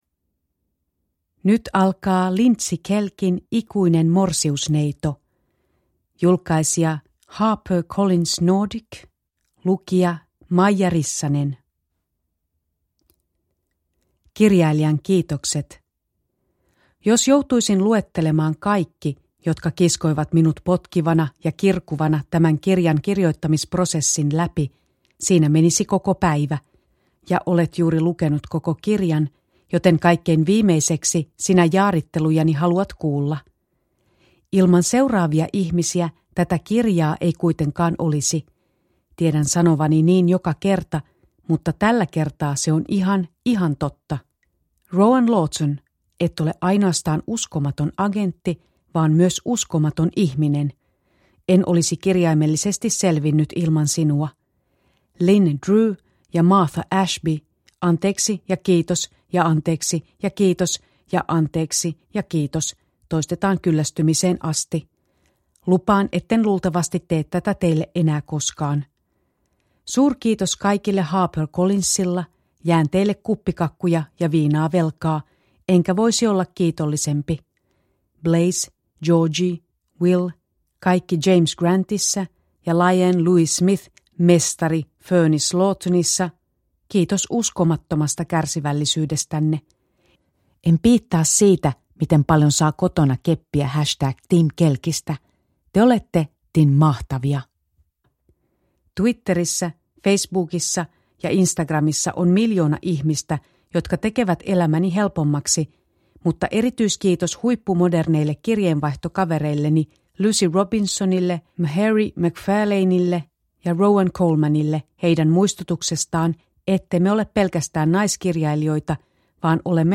Ikuinen morsiusneito – Ljudbok – Laddas ner